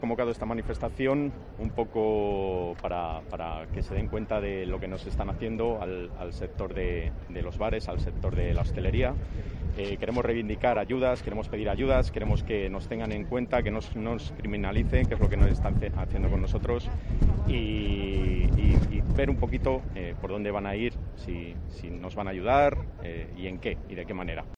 CONCENTRACIÓN EN LA PLAZA MAYOR DE VALLADOLID
Los hosteleros del ocio nocturno se han concentrado en la Plaza Mayor de Valladolid